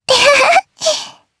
Lewsia_A-Vox_Happy3_jp.wav